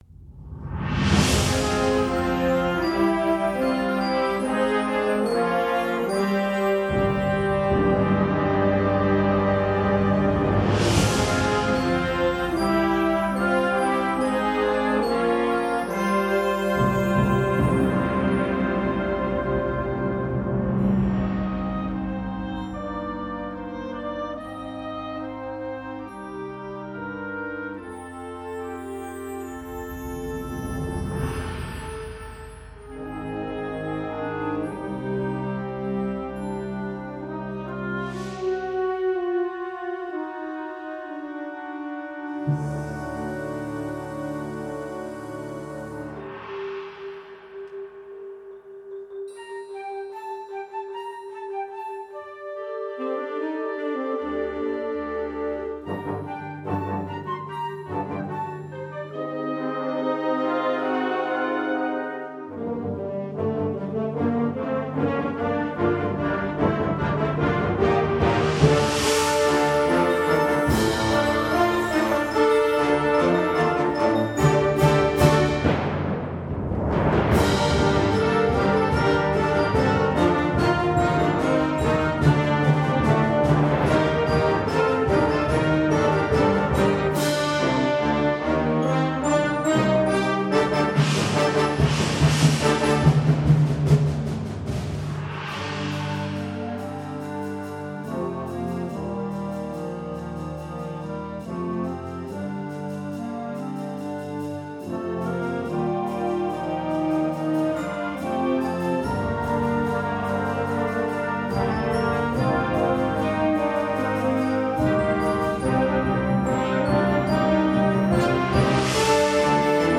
Gattung: Konzertant
Besetzung: Blasorchester